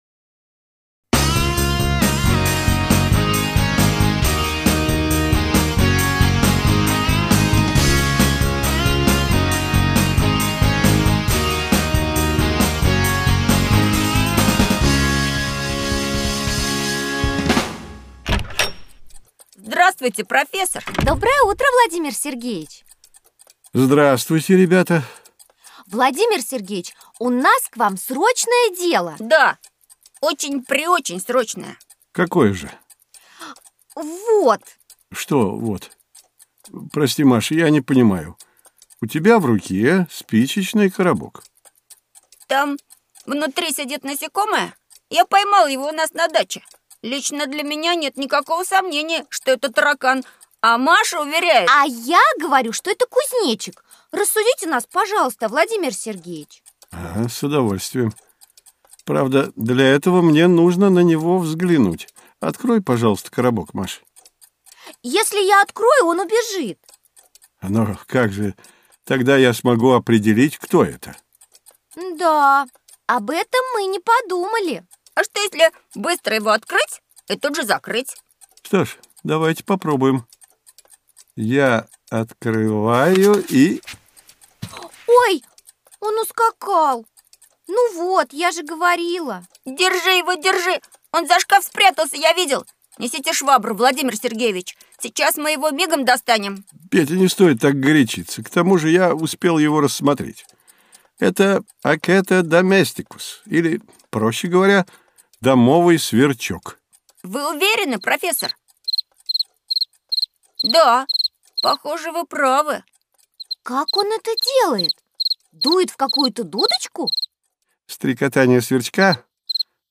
Аудиокнига Развивающая аудиоэнциклопедия. Как стать натуралистом | Библиотека аудиокниг